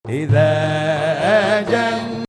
Ajam 4
melody repeat